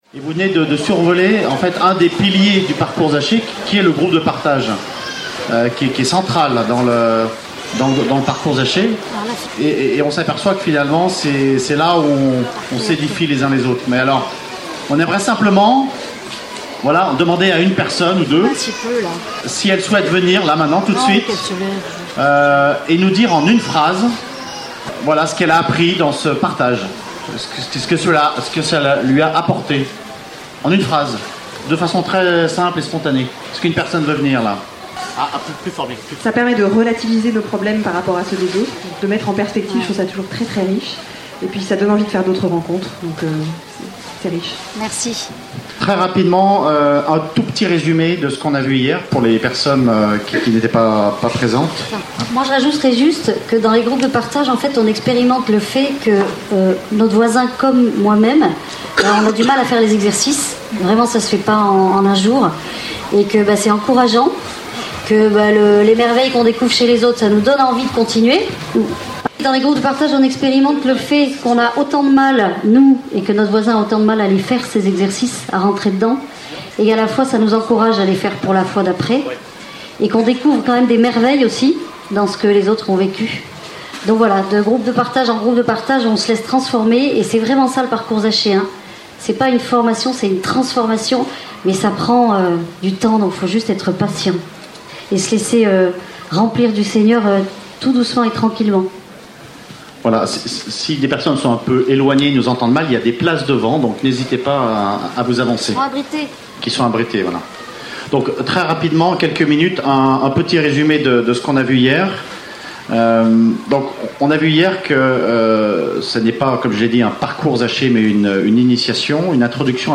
Enseignement
Session famille 3 (du 4 au 9 août 2012)
Format :MP3 64Kbps Mono